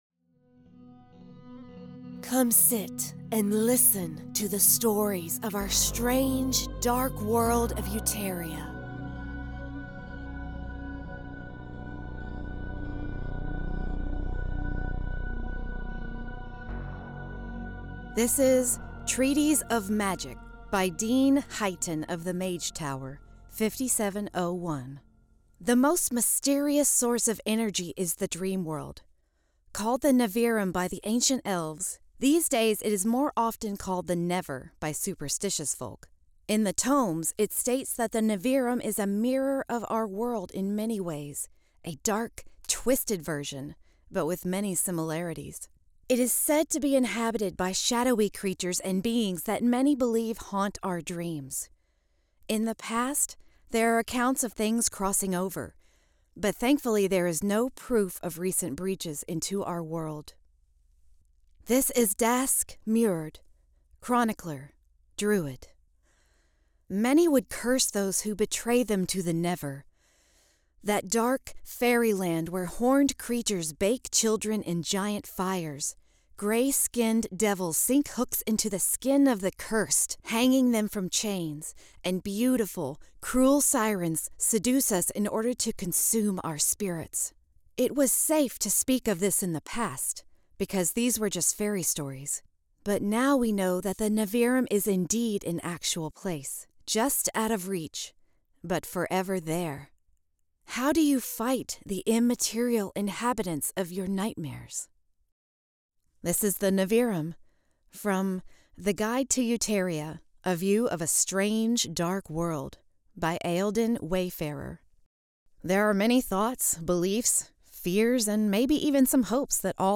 A human written, narrated, and created podcast about the setting for the Sagaborn Roleplaying game and the Dark Return Setting.